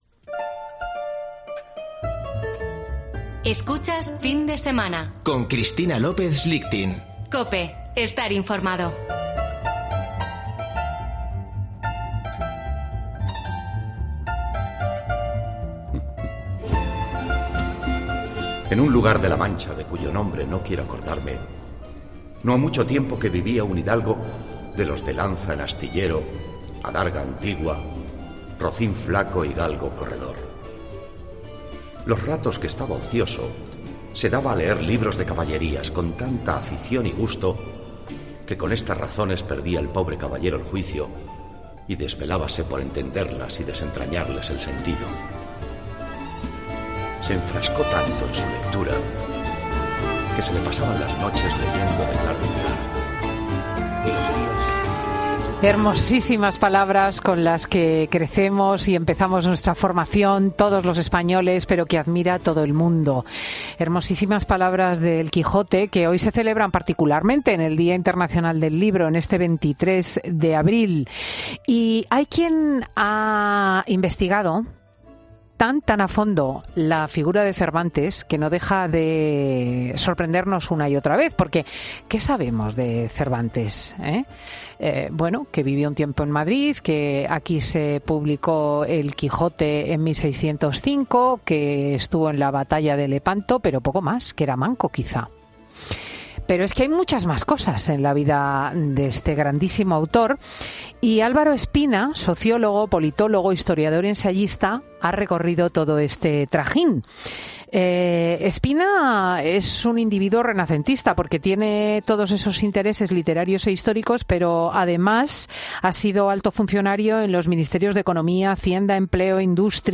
Álvaro Espina, sociólogo, politólogo, historiador y ensayista, presenta en Fin de Semana con Cristina su nuevo libro sobre 'Cerbantes'